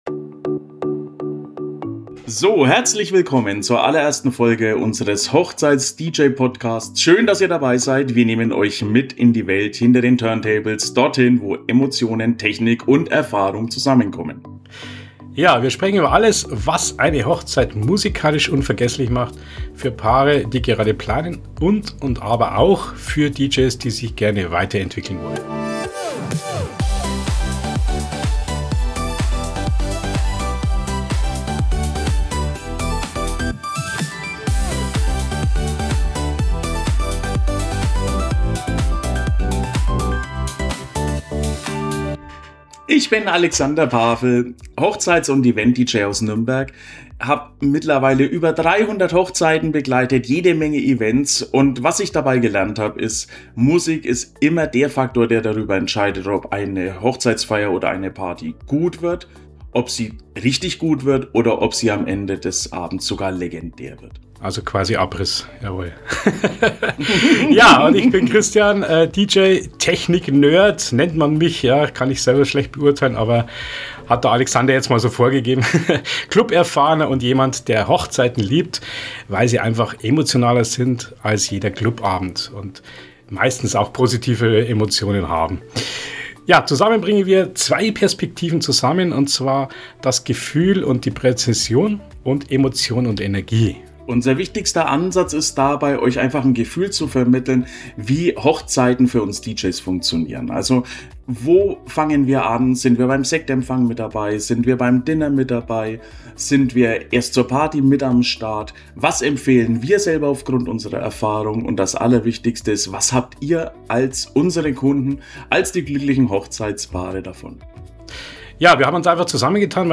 Zwei erfahrene DJs, zwei Perspektiven, ein gemeinsames Ziel: Hochzeiten musikalisch zum unvergesslichen Event machen.